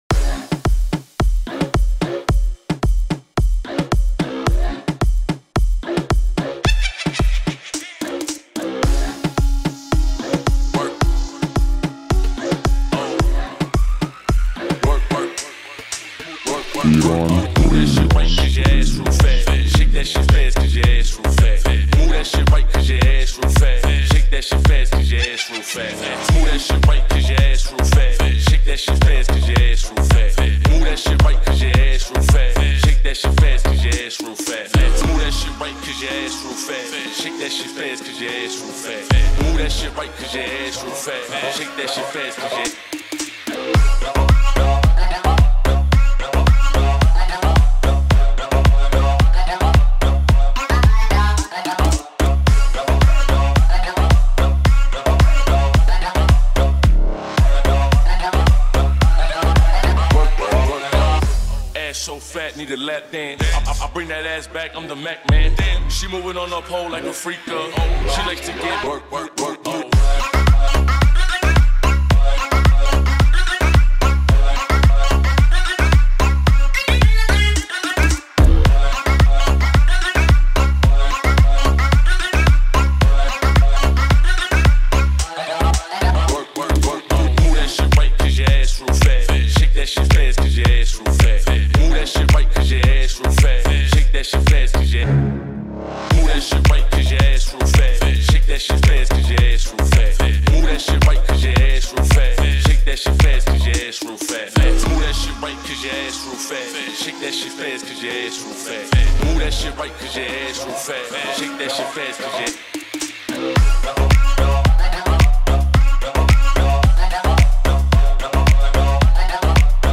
Download house remix for club 2019